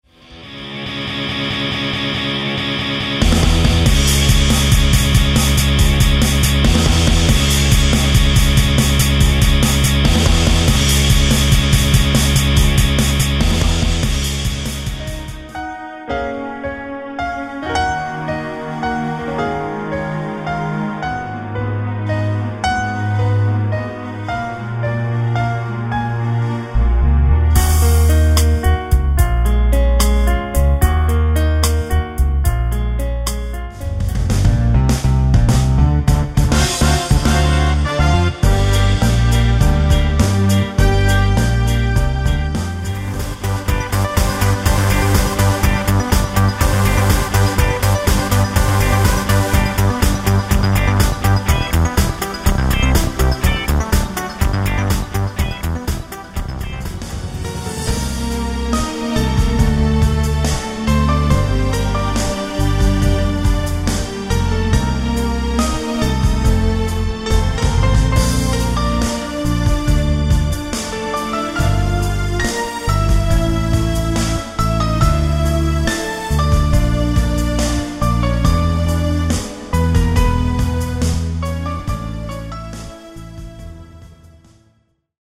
Демо микс написанных мною аранжировок